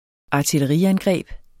Udtale [ ˌɑːtelʌˈʁi- ]